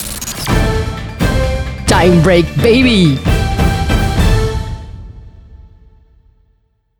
• Voix féminine / urbaine
SWEEP_-_TIME_BREAK_BABY.wav